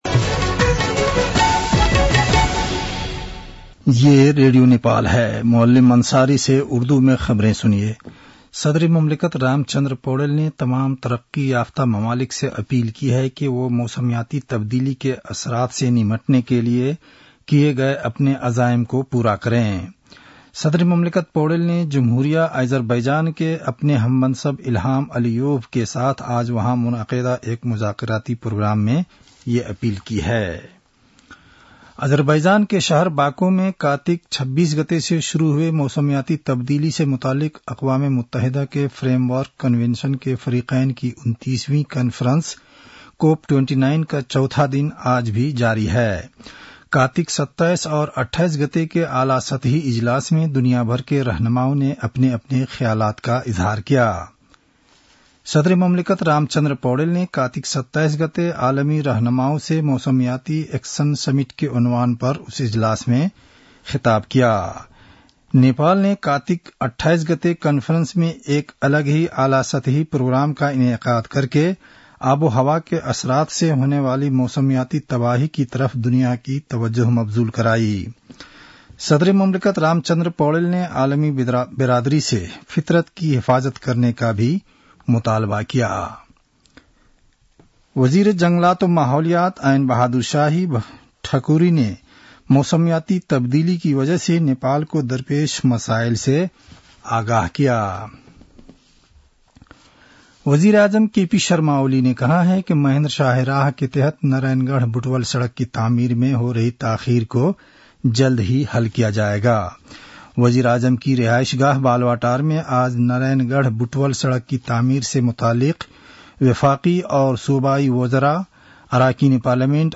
उर्दु भाषामा समाचार : ३० कार्तिक , २०८१
Urdu-news-7-29.mp3